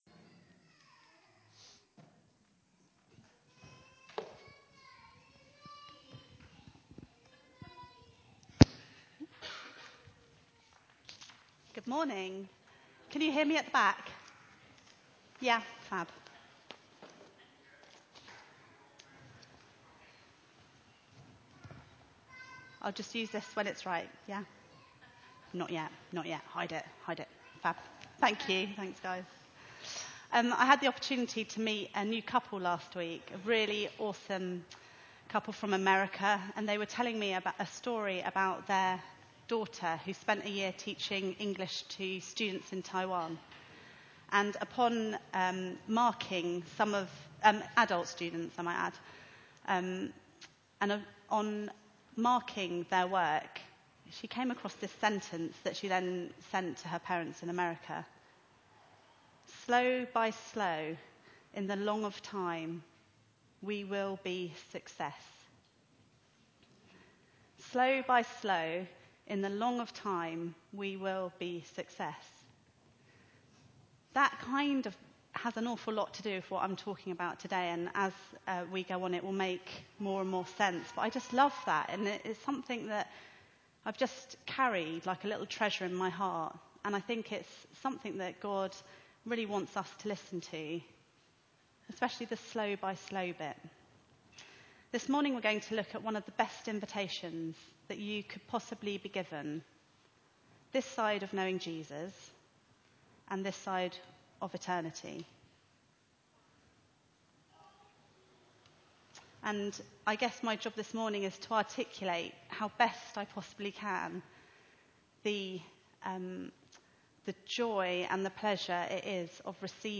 Matthew 11:28-30 Service Type: Sunday Meeting Bible Text